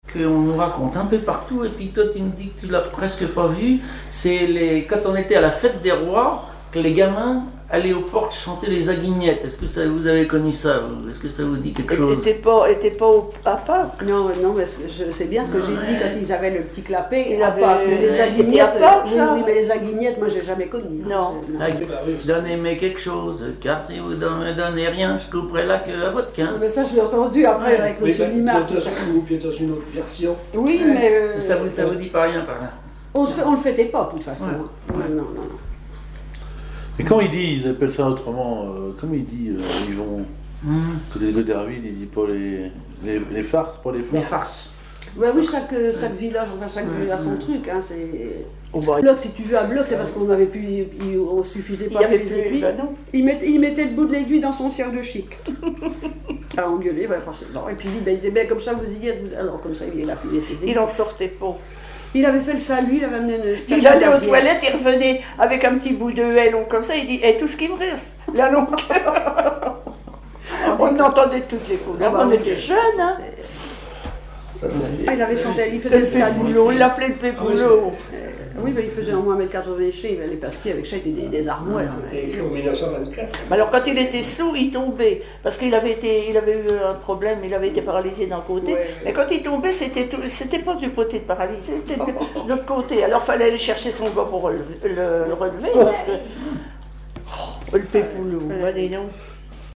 Genre strophique
Chansons et commentaires
Pièce musicale inédite